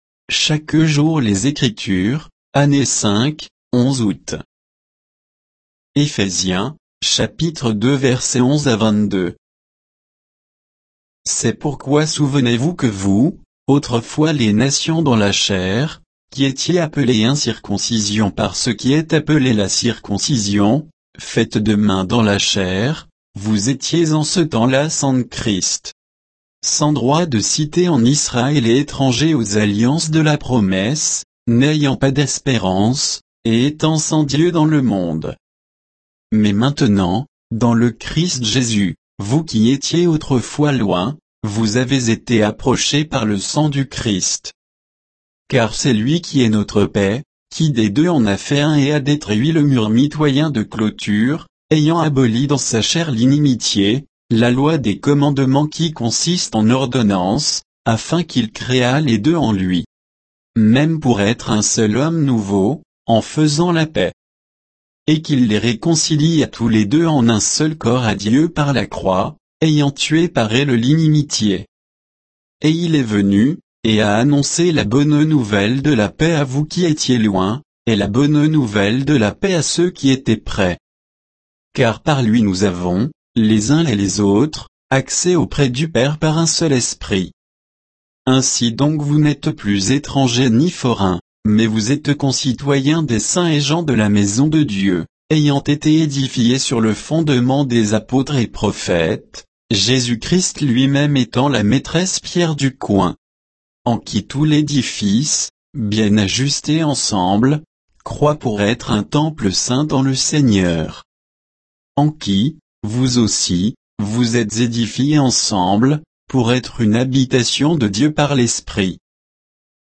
Méditation quoditienne de Chaque jour les Écritures sur Éphésiens 2, 11 à 22